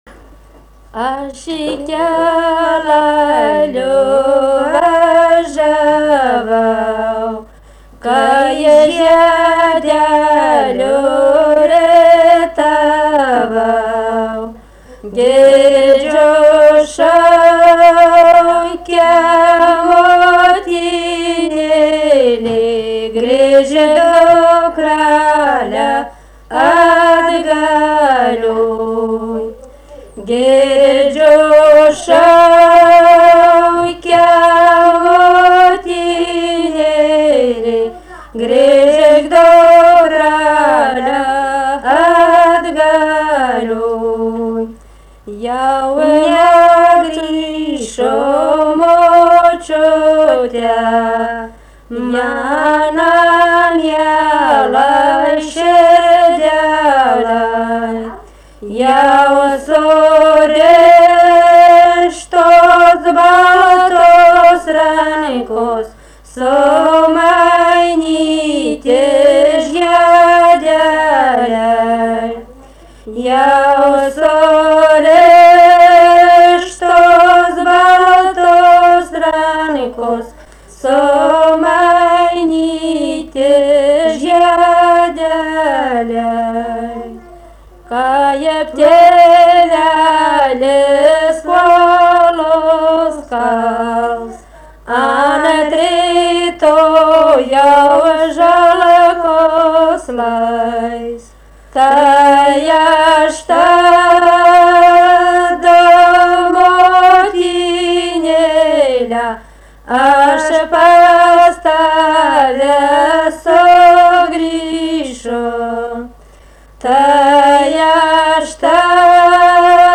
LMTA Muzikinio folkloro archyvas · omeka